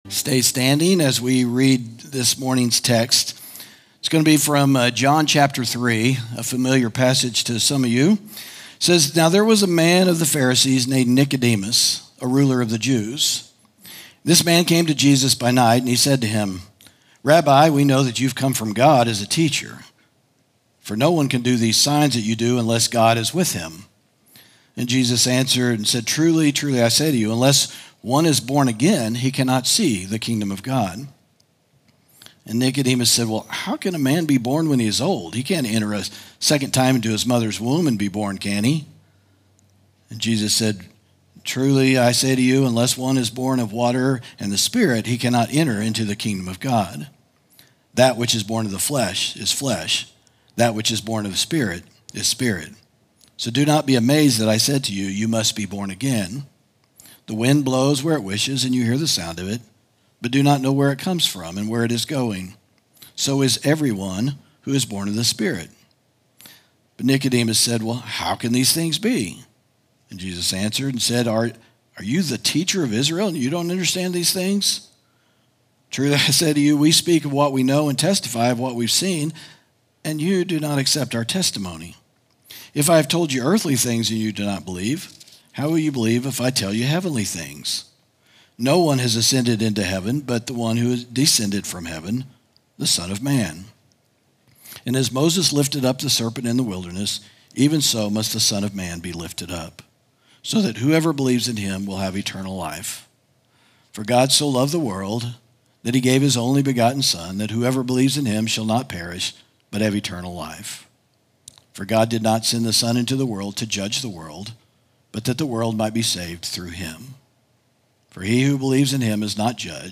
sermon audio 0215.mp3